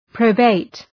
Προφορά
{‘prəʋbeıt}
probate.mp3